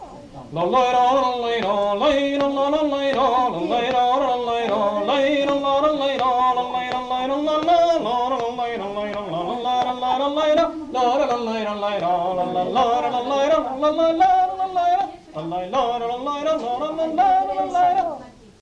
Aire culturelle : Couserans
Genre : chant
Effectif : 1
Type de voix : voix d'homme
Production du son : fredonné
Danse : bourrée